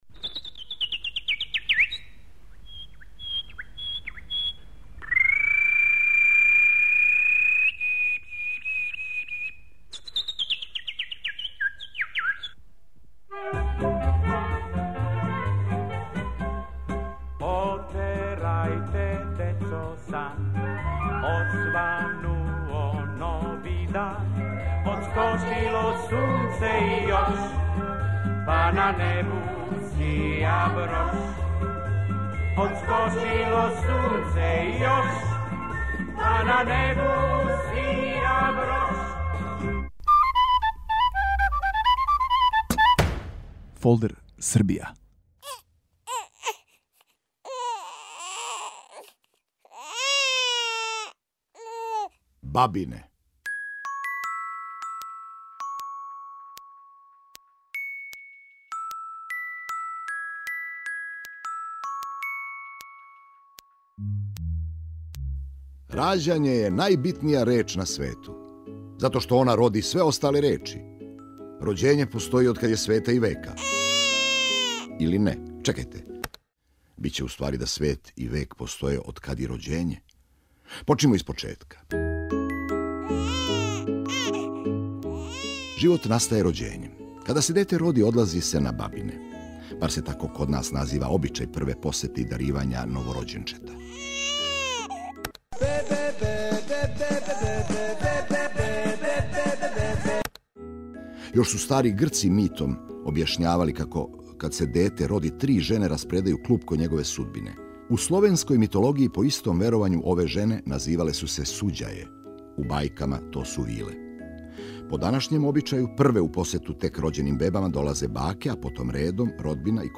а ваш нови водич кроз Србију је глумац Бранимир Брстина.